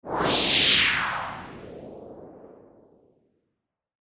/ F｜演出・アニメ・心理 / F-22 ｜Move whoosh(動く、移動する)
whoosh　A2